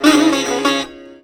SITAR GRV 06.wav